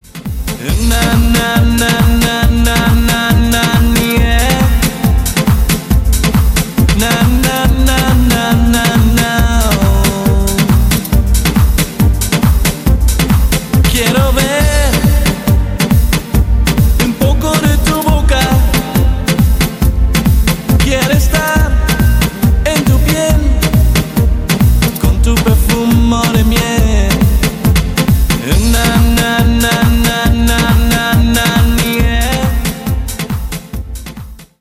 • Качество: 160, Stereo
мужской вокал
громкие
спокойные
Стиль: house